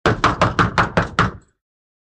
Стук в дверь